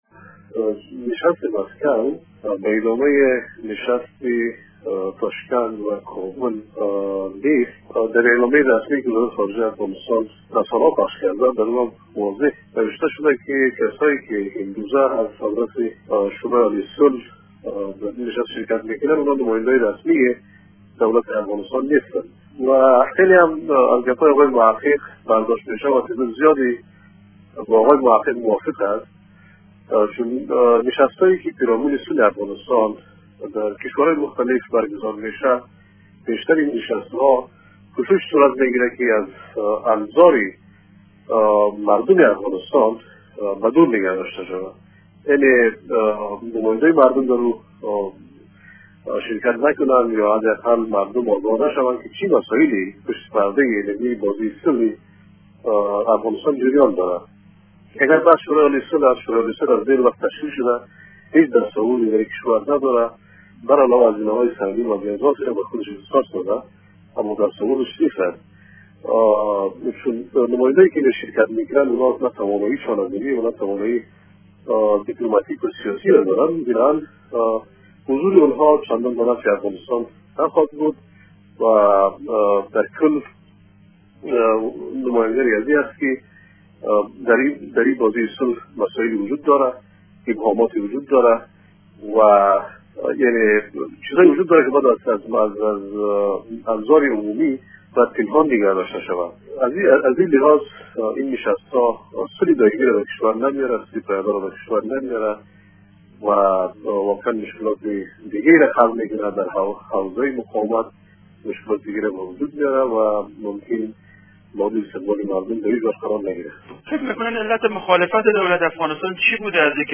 کارشناس سیاسی با انتقاد از حضور نداشتن احزاب سیاسی در نشست مسکو گفت